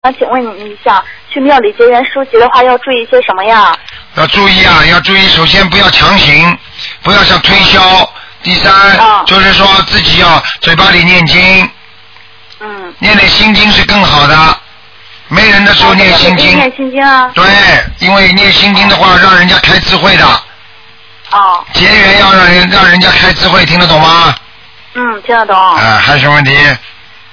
目录：2012年03月_剪辑电台节目录音集锦